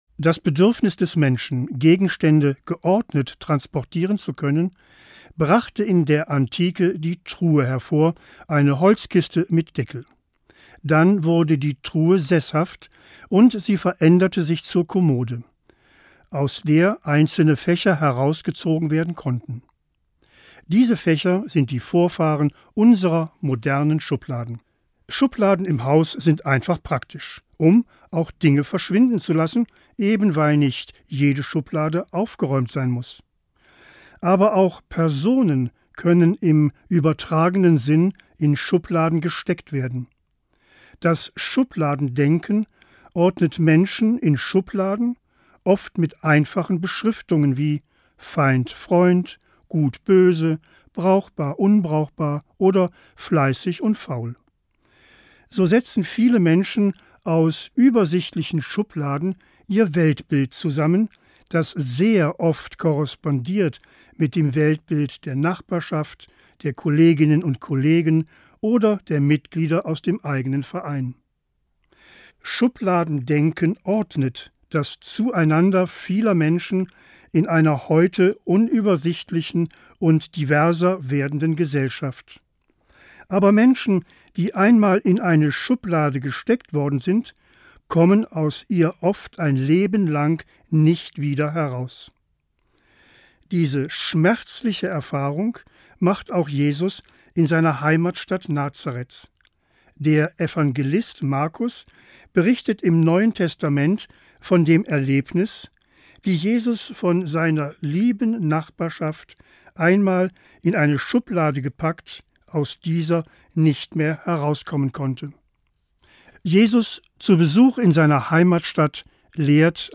Morgenandacht 12.10.